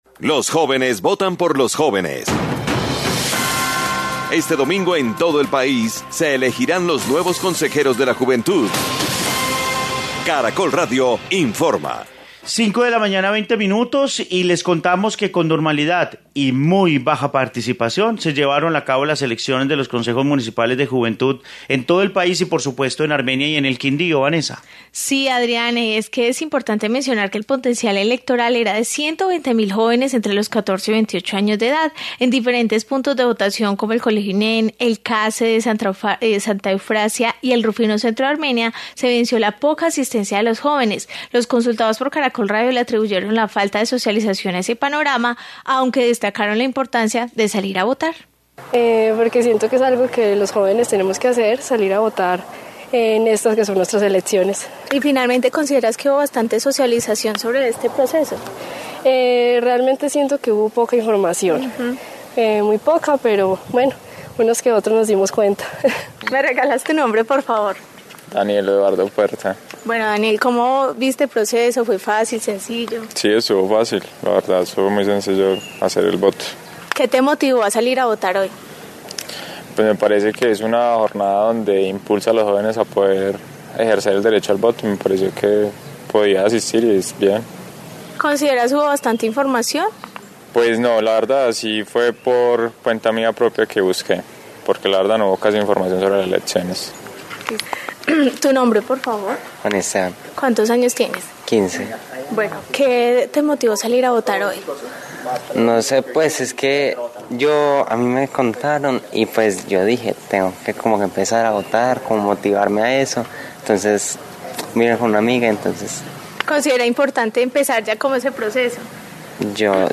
Informe sobre balance de jornada electoral